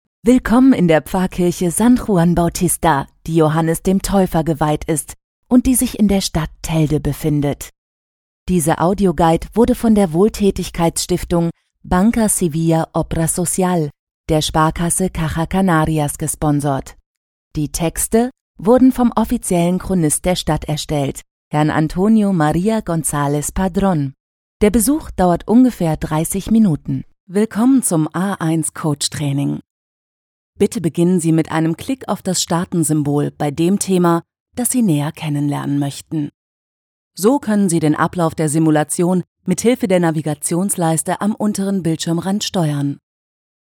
Sprecherin, Werbesprecherin, Hörspiel-Sprecherin,Mikrofonstimme
Kein Dialekt
Sprechprobe: eLearning (Muttersprache):